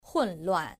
• hùnluàn